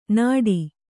♪ nāḍi